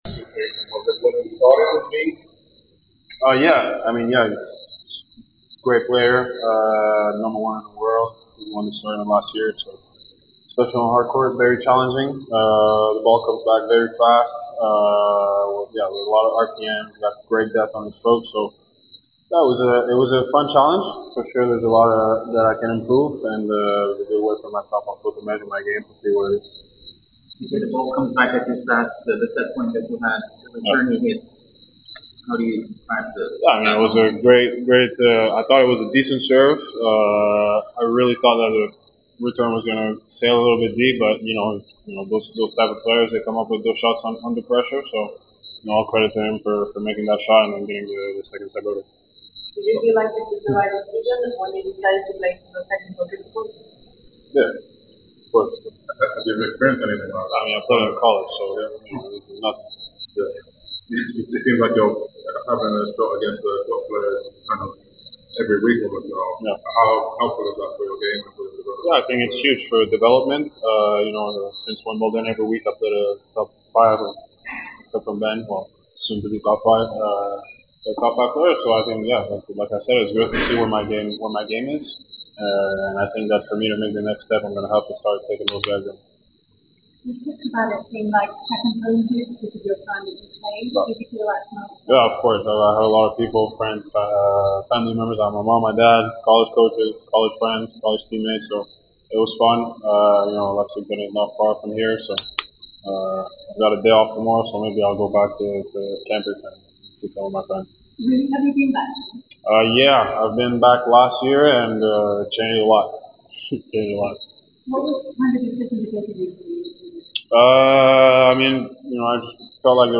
Gabriel Diallo post-match interview after losing to Jannik Sinner 2-6, 6-7 in the 3rd Round of the Cincinnati Open.